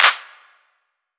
Clap
ED Claps 01.wav